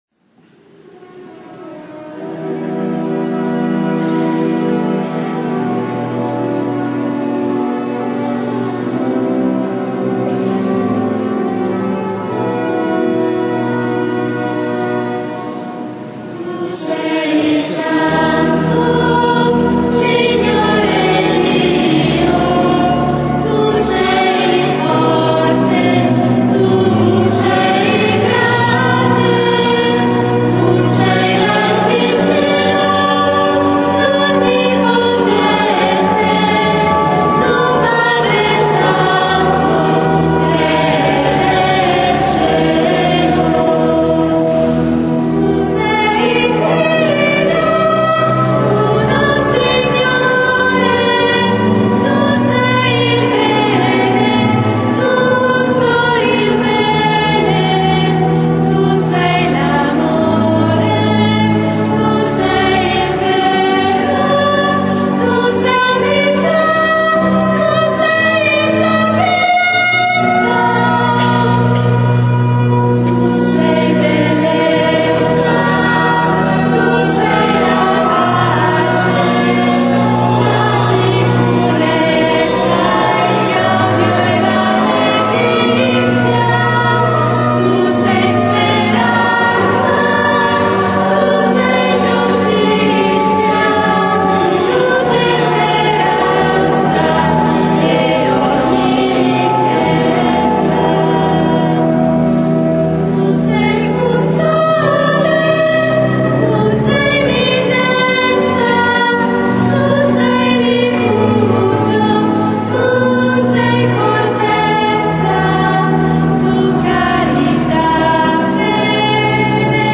III Dom. di Pasqua (Prime Comunioni)
canto: